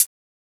Closed Hats
HAT - Adam and Eve.wav